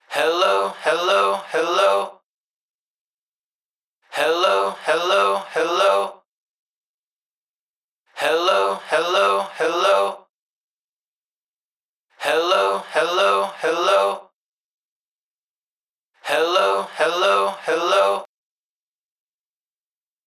Recorded at Beware of Dog Studios - Chicago, IL 2011-2012